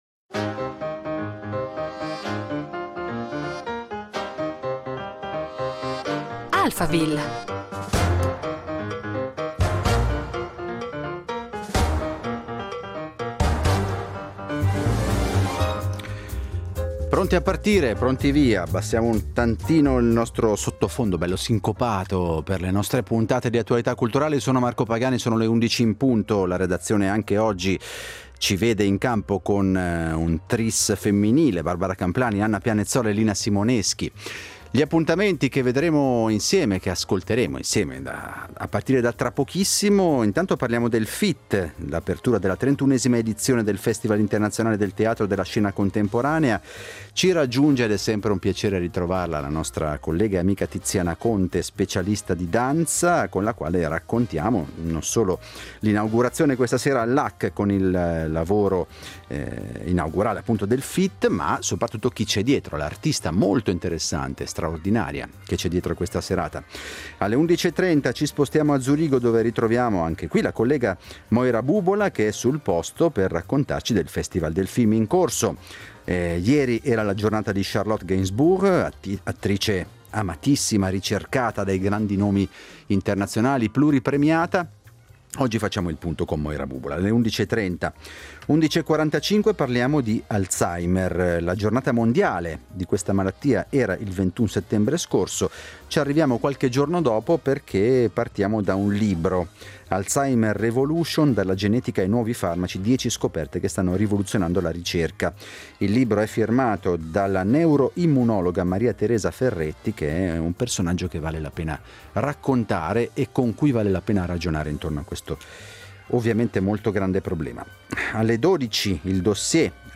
ha intervistato l’artista durante le prove dello spettacolo